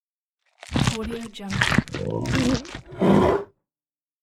Lion Eating Botão de Som